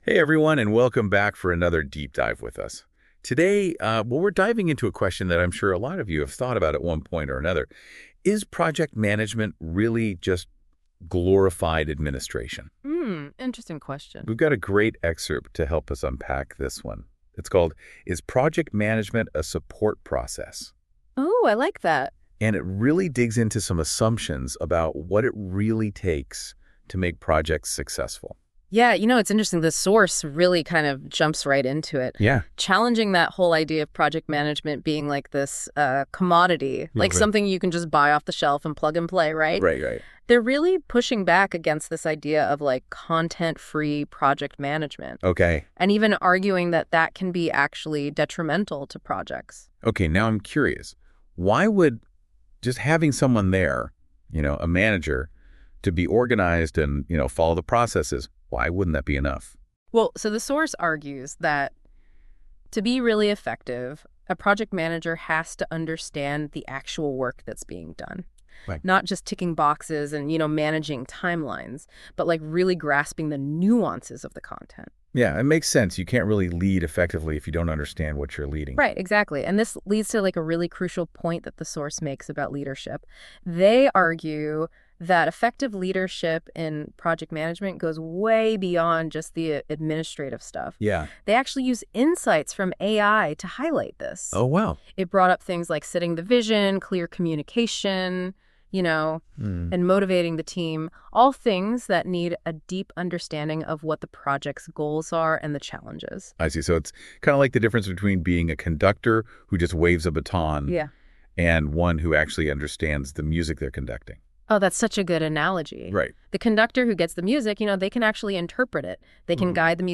Hier als Podcast in englischer Sprache, generiert von Notebook LM